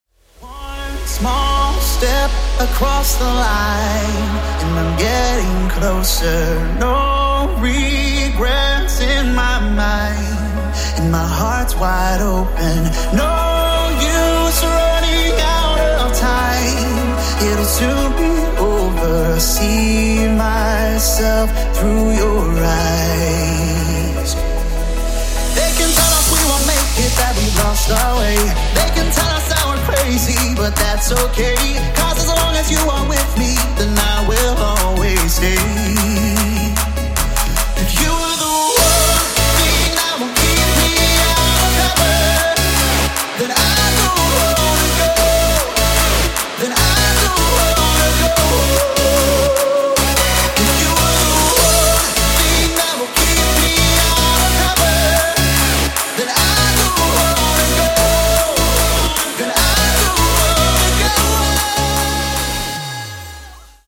мужской вокал
dance
Electronic
EDM
club
vocal